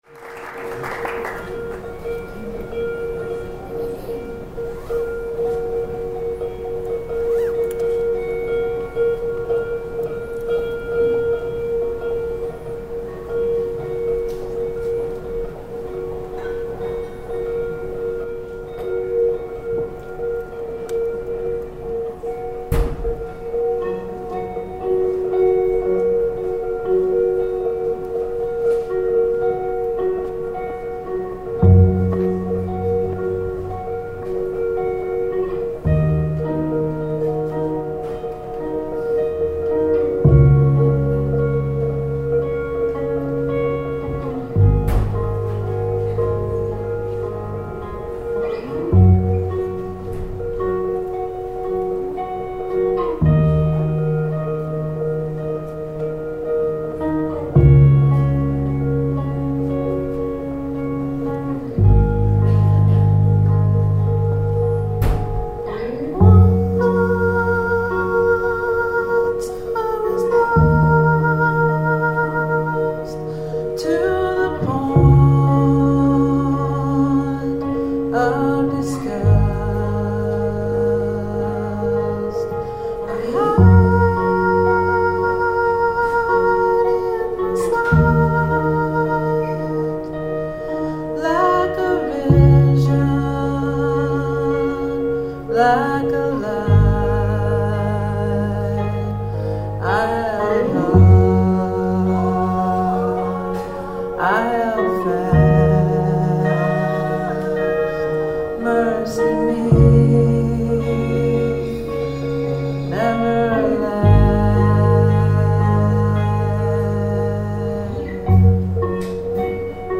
Slow-Core.